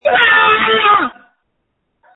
Screams from December 24, 2020
• When you call, we record you making sounds. Hopefully screaming.